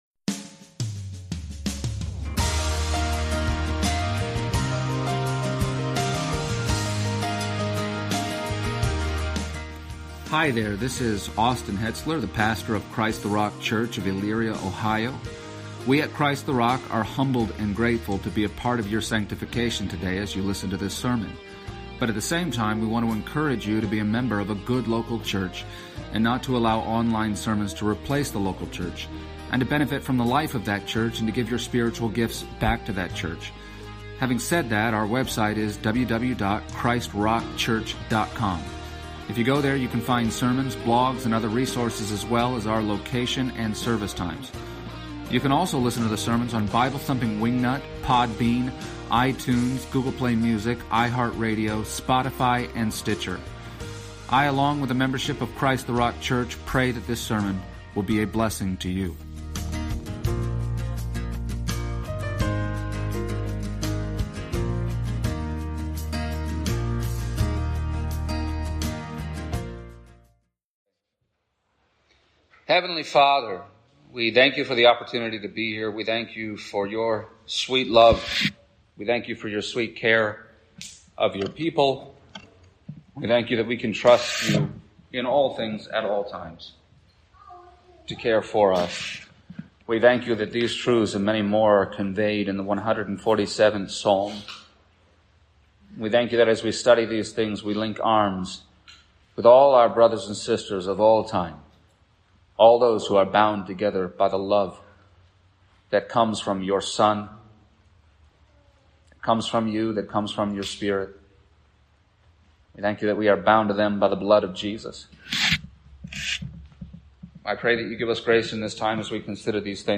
Preacher
Psalm 147:1-20 Service Type: Special event %todo_render% « Psalm 147